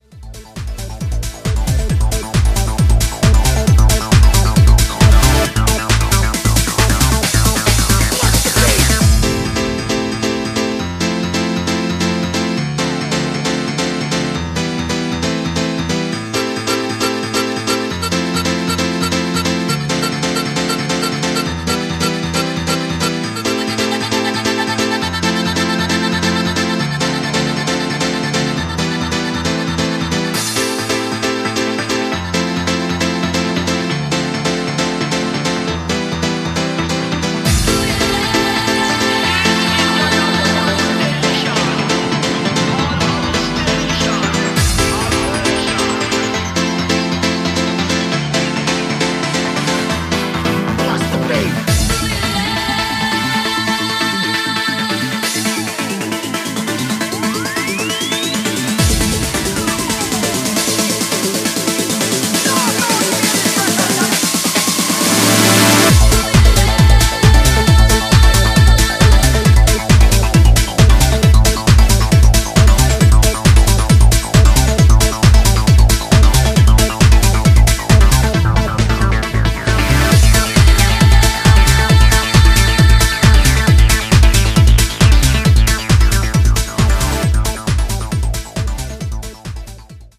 90sプログレッシヴ・ハウス/トランスの影響を昇華した極彩色の楽曲群を展開